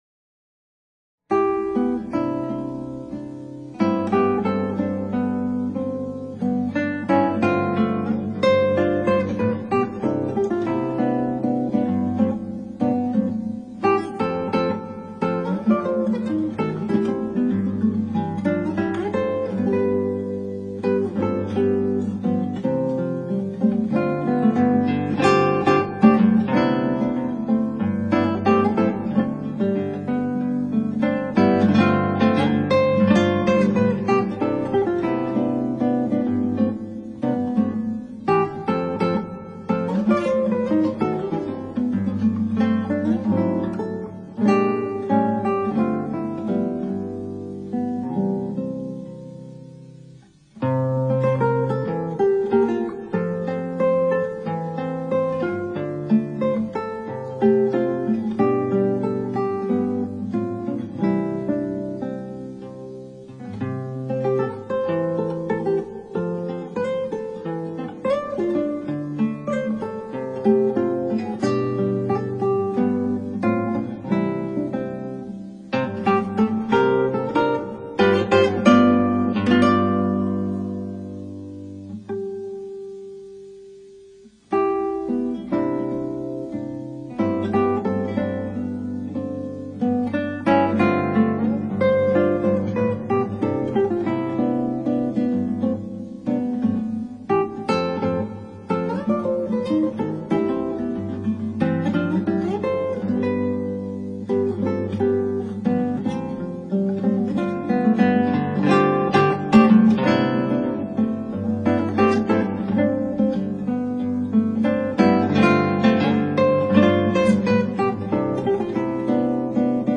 クラシックギター　ストリーミング　コンサートサイト
答え　変拍子バリバリ　左手ちょ～ムズイ　不思議な響きの三重苦？喜び？。